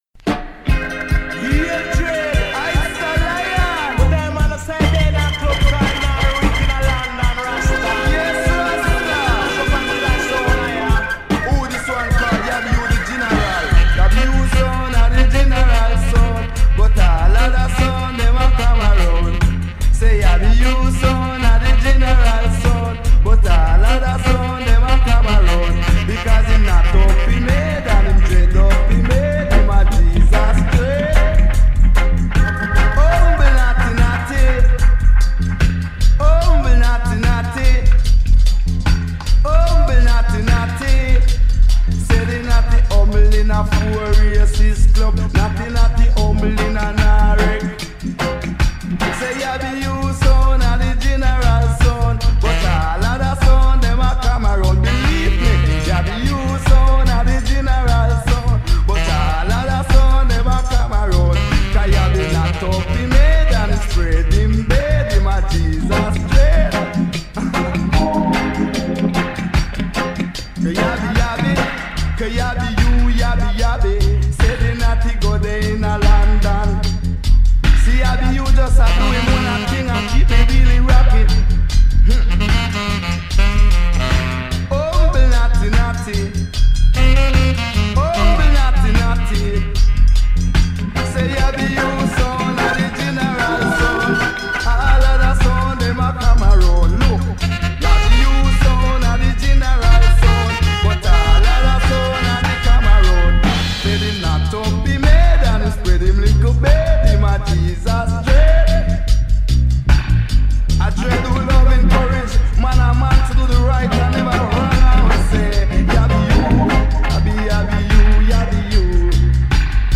jesus_dread_dub.mp3